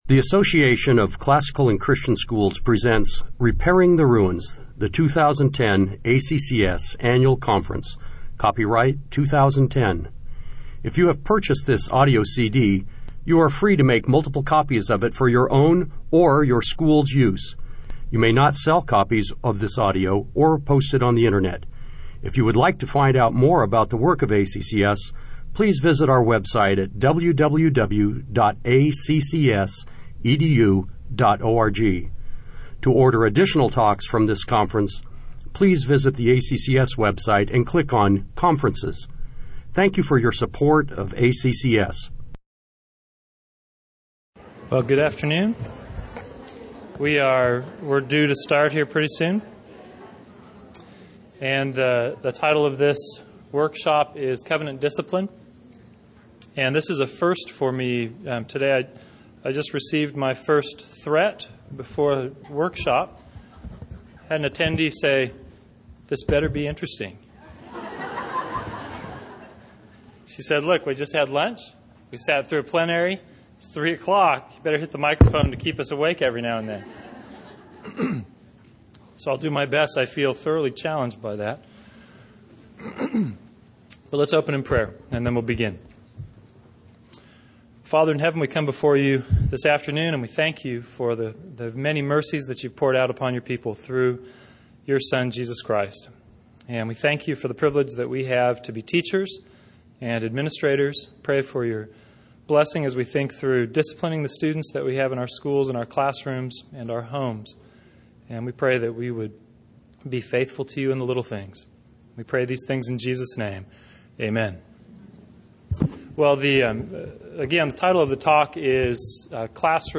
2010 Workshop Talk | 1:02:03 | All Grade Levels, Virtue, Character, Discipline